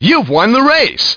1 channel
place-youwonrace.mp3